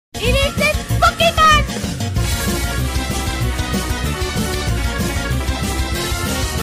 Meme Sound Effects